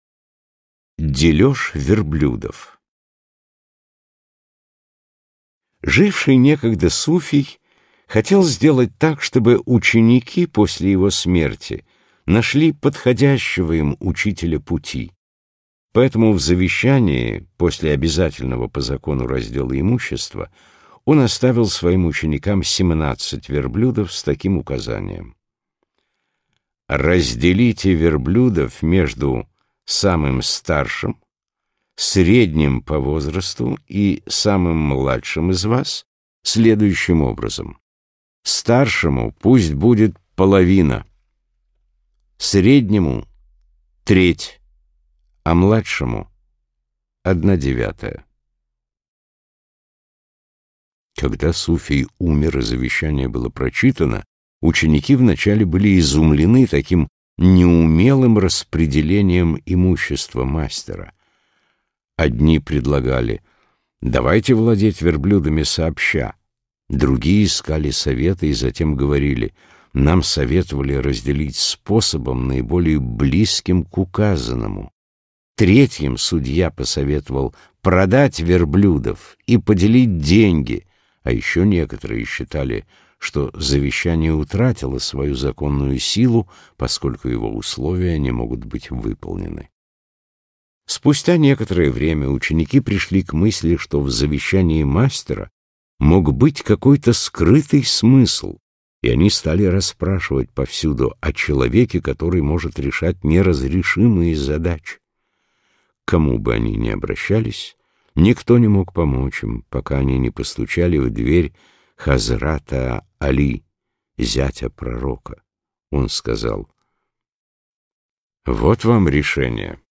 Аудиокнига Суфийские притчи | Библиотека аудиокниг